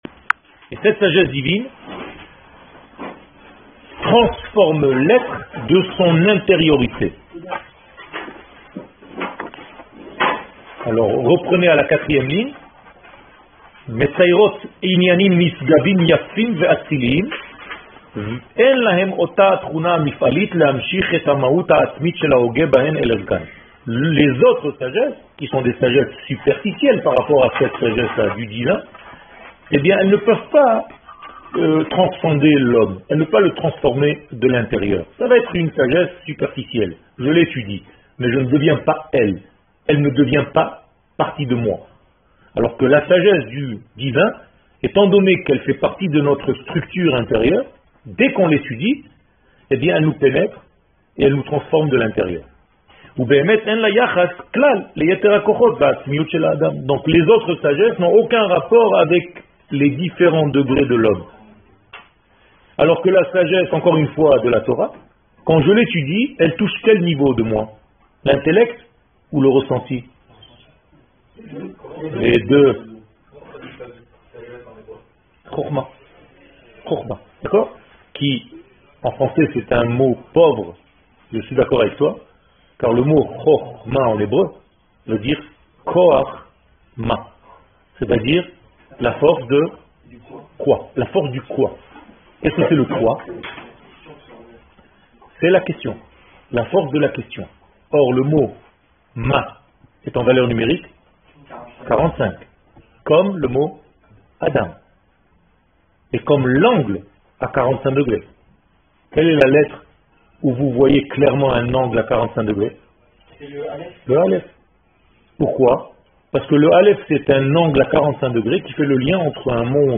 Orot ha kodech 1 Eretz Israel שיעור מ 05 נובמבר 2017 53MIN הורדה בקובץ אודיו MP3 (24.41 Mo) הורדה בקובץ אודיו M4A (6.76 Mo) TAGS : Secrets d'Eretz Israel Etude sur la Gueoula Emouna Torah et identite d'Israel שיעורים קצרים